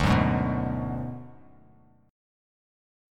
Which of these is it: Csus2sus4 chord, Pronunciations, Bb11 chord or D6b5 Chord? D6b5 Chord